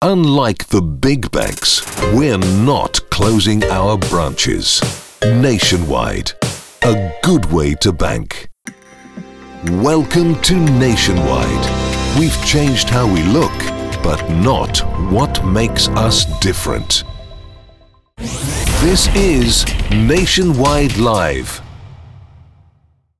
Anglais (britannique)
Promotions
Aston Spirit microphone
Fully acoustic/sound treated recording environment